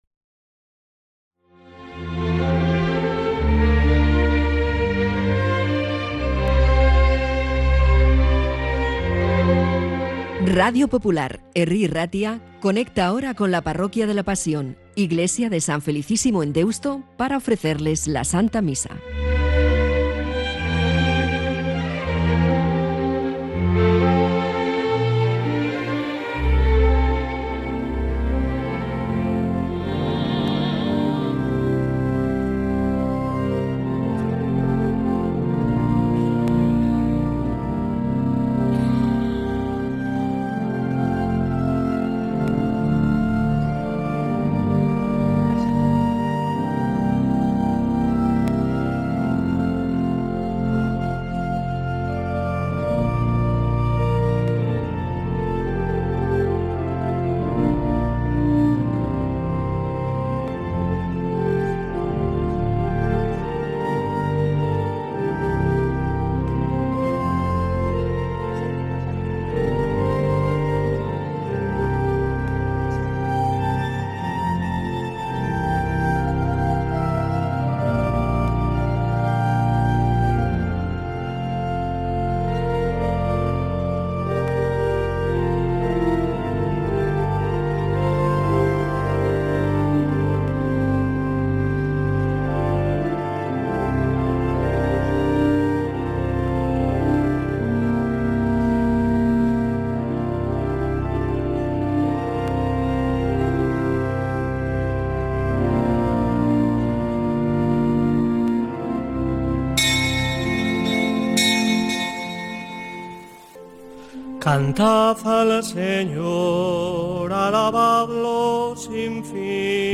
Todos los domingos a las 8.00h , Radio Popular-Herri Irratia ofrece la retrasmisión de la Santa Misa desde San Felicísimo en Deusto